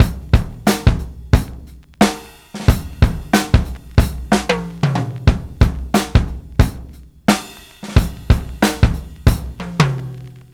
Rototomfoolery 091bpm